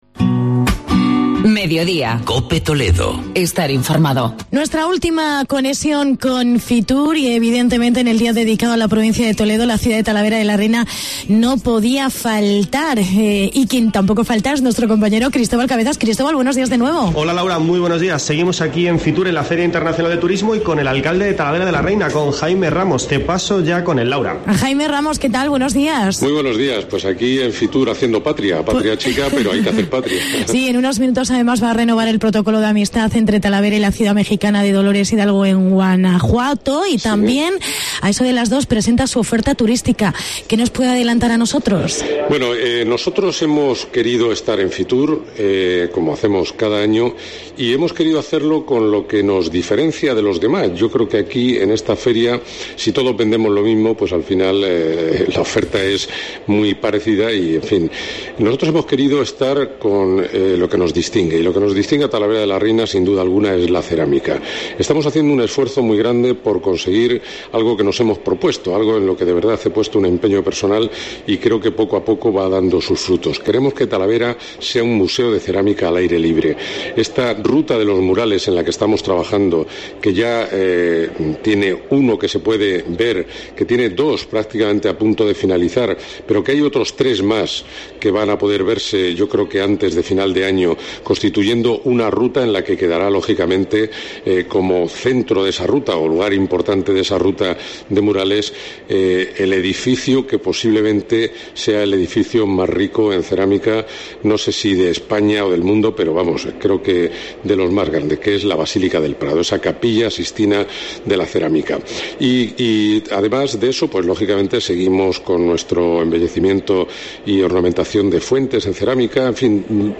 Entrevista con el alcalde de Talavera: Jaime Ramos